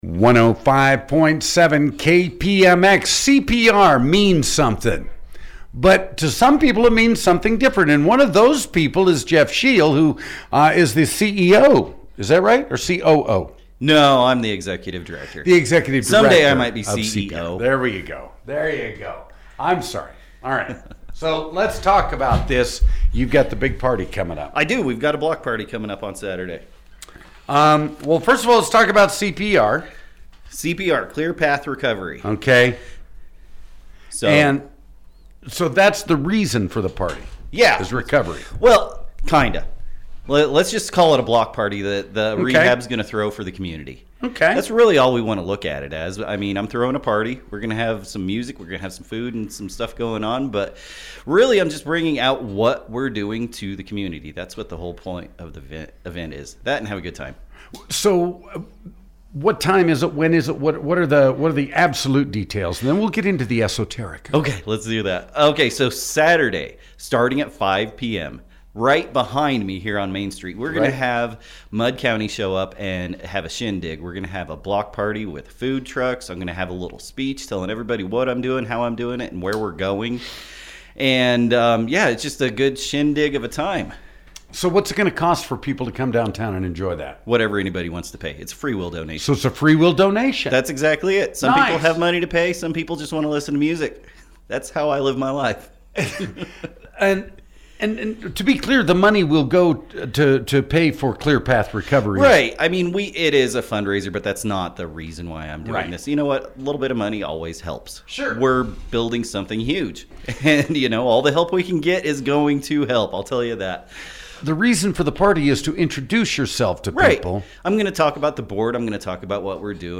Listen to our radio spot from this morning!